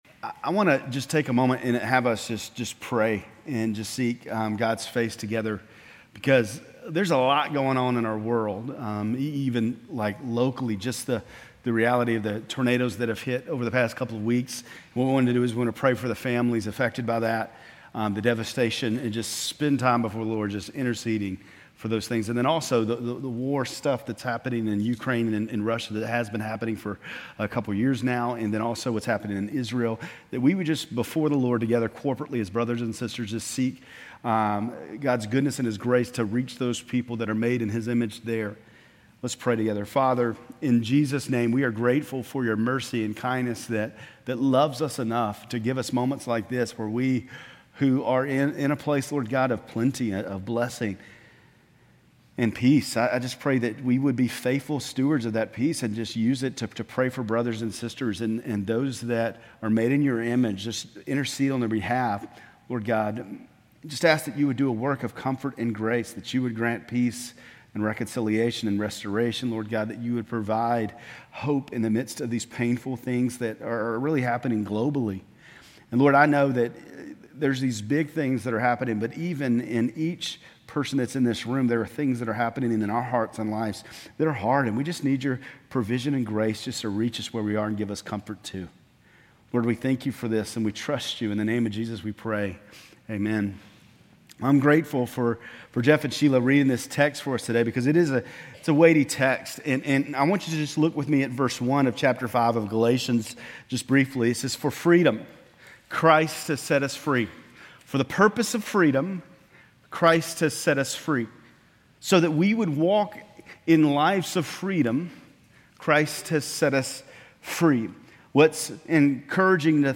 Grace Community Church Lindale Campus Sermons Galatians 5:1-15 May 27 2024 | 00:24:52 1x 00:00 / 00:24:52 Subscribe Share RSS Feed Share Link Embed